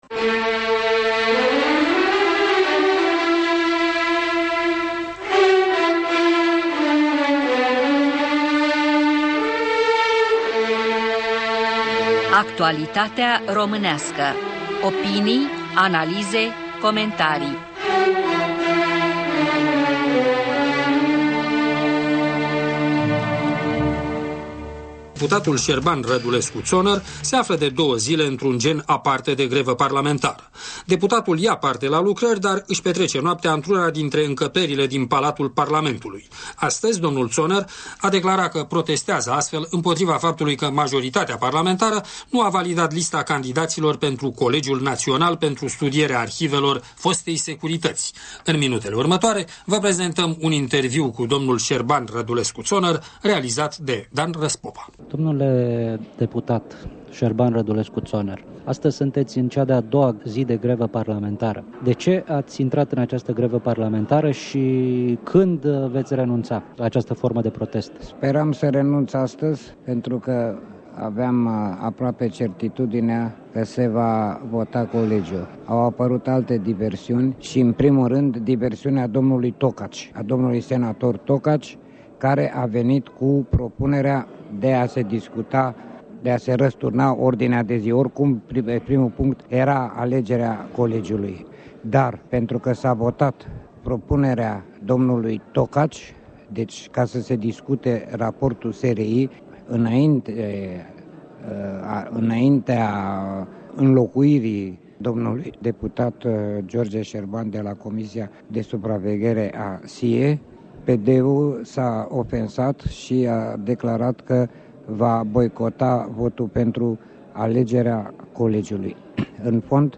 Interviu cu deputatul Șerban Rădulescu-Zoner